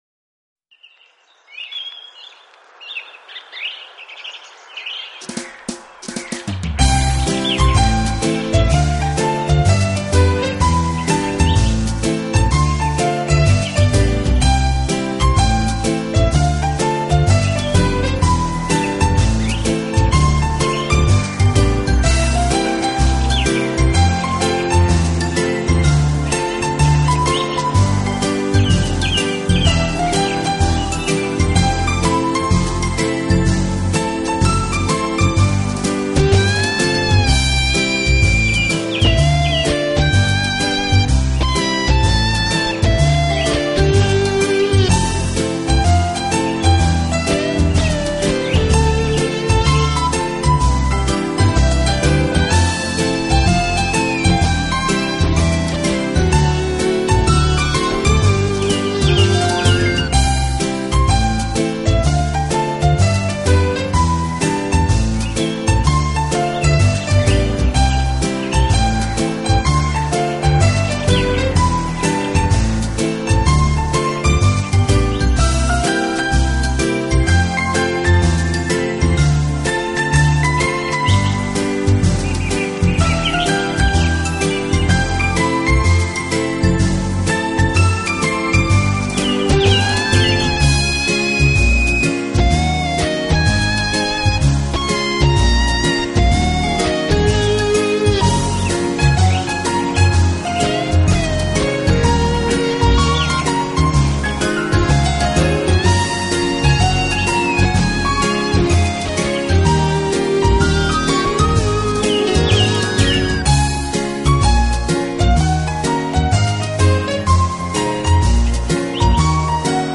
Genre: Rock/Pop Albums, Classical CDs, Easy Listening, Rock